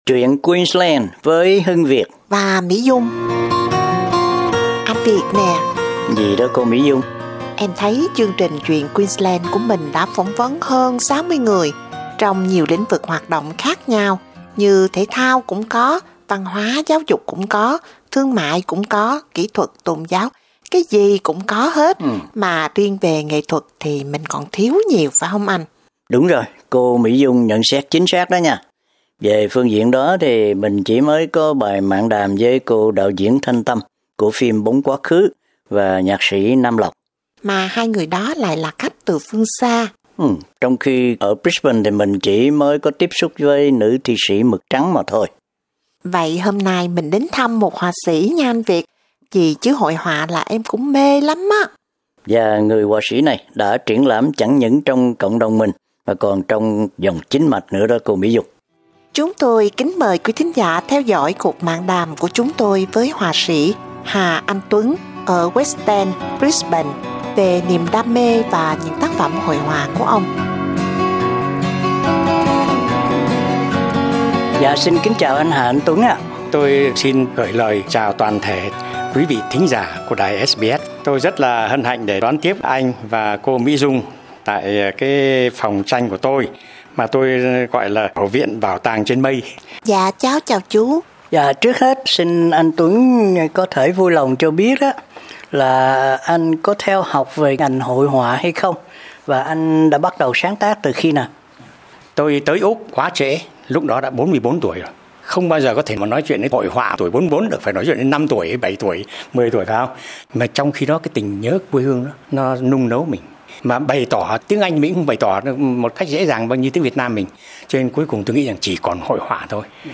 cuộc mạn đàm